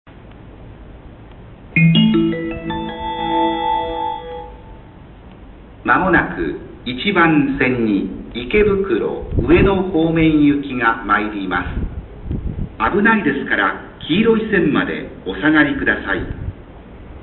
boundfor-ikebukuro&ueno.mp3